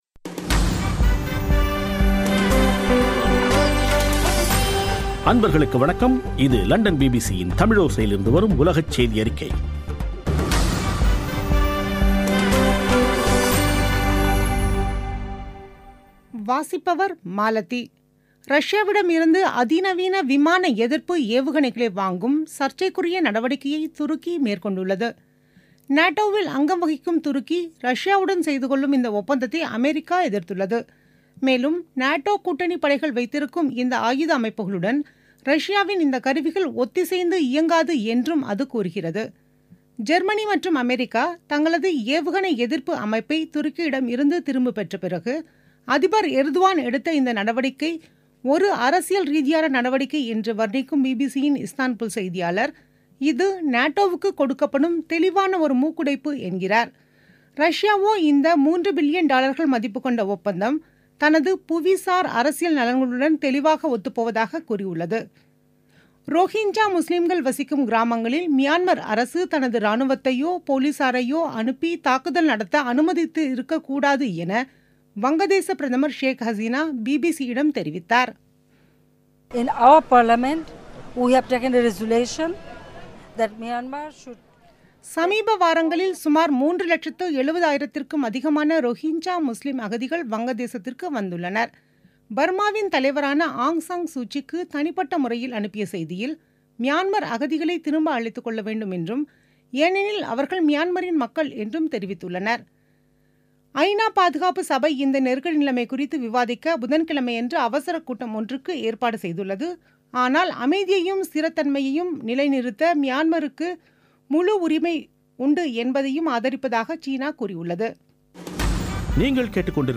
பிபிசி தமிழோசை செய்தியறிக்கை (12/09/2017)